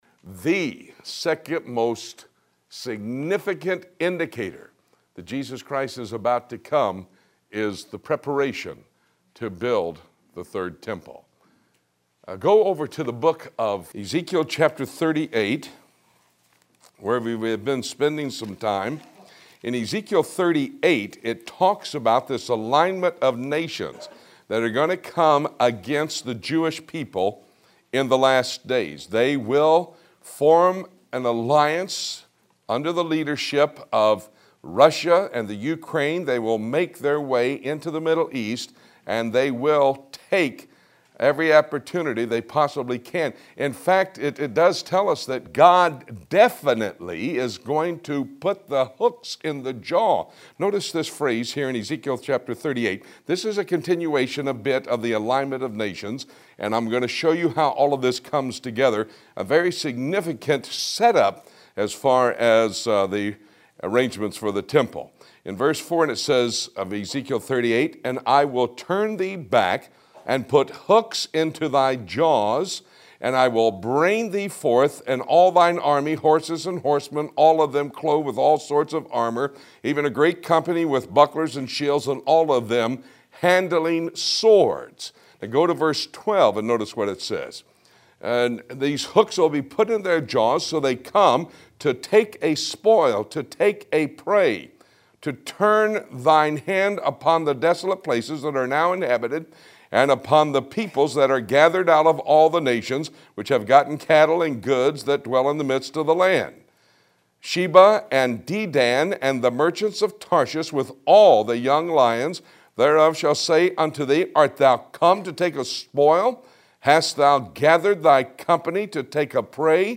Trend Four - Arrangements Arrangements for the Temple Ezekiel 40 - 46 Listen to the audio of this lesson Download the audio of this lesson.